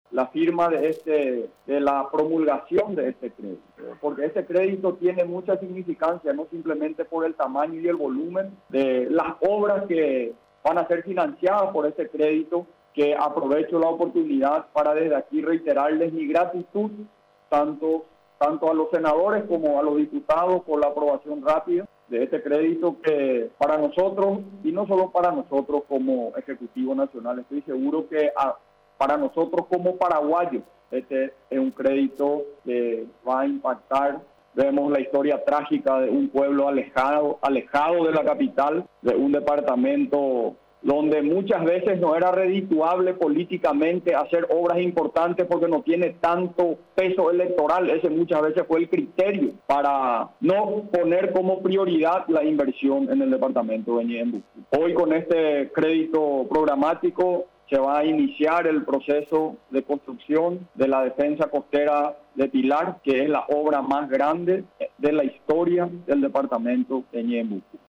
El presidente de la República, Mario Abdo Benítez, resaltó que la aprobación de este millonario crédito, tendrá por destino la ampliación de la defensa costera de la ciudad de Pilar.